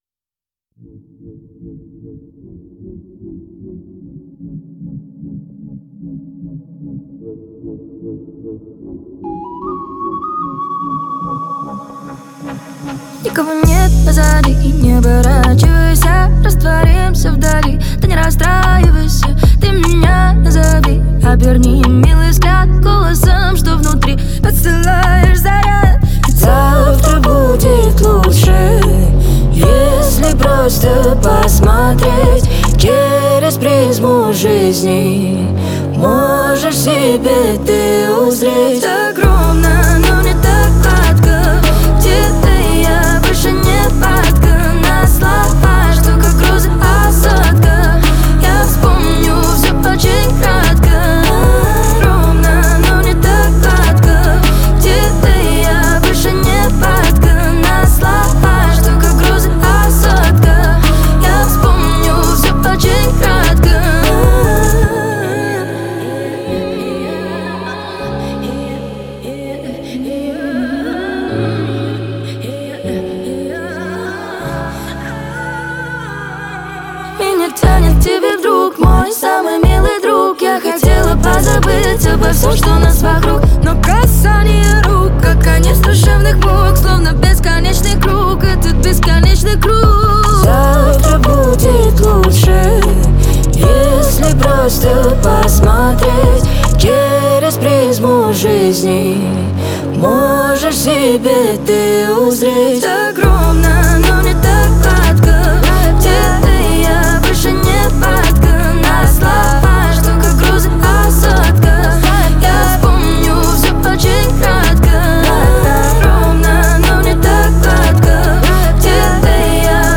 уверенное вокальное исполнение